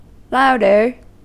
Ääntäminen
Ääntäminen US Tuntematon aksentti: IPA : /laʊdə(ɹ)/ Haettu sana löytyi näillä lähdekielillä: englanti Käännöksiä ei löytynyt valitulle kohdekielelle. Louder on sanan loud komparatiivi.